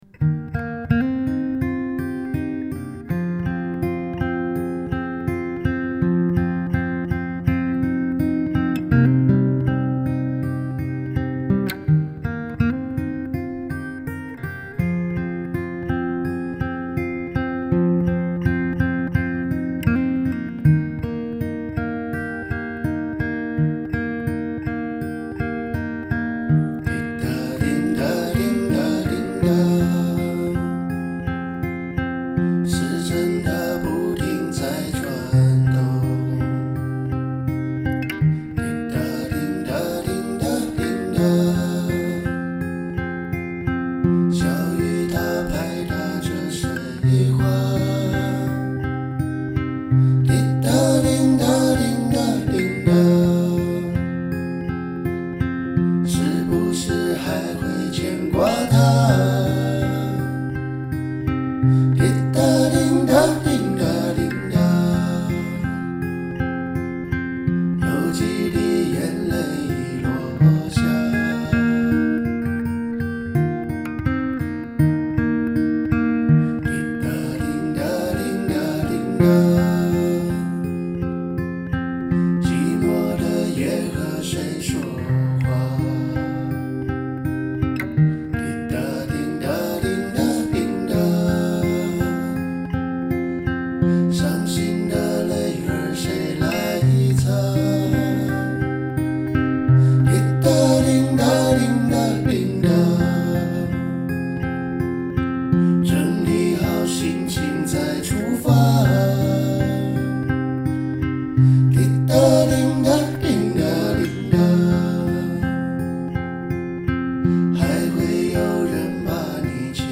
3品C调指法
弹唱谱